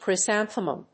音節chry・san・the・mum 発音記号・読み方
/krəsˈænθəməm(米国英語)/